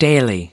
/ˈeɪnʃənt/